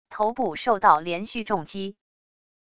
头部受到连续重击wav音频